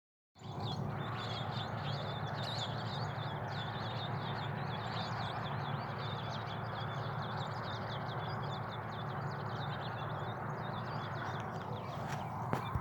Brown-and-yellow Marshbird (Pseudoleistes virescens)
Detailed location: Camino a El Duraznal
Condition: Wild
Certainty: Observed, Recorded vocal
Pecho-amarillo-comun_1.mp3